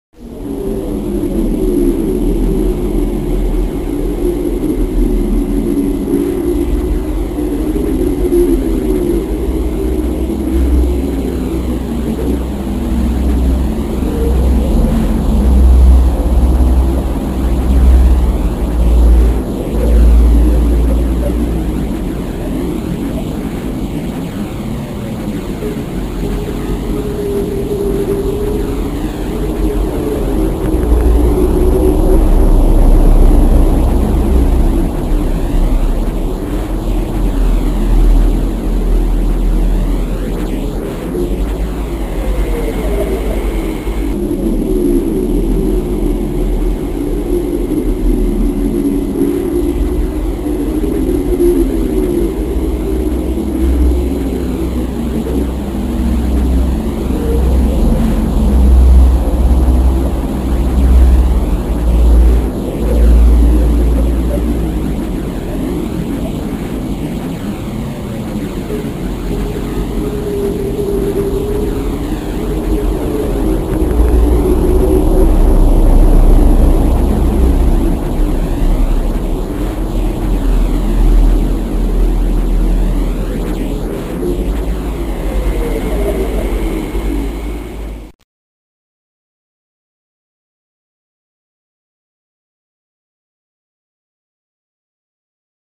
Звук Земли з космосу унікальний запис NASA з орбіти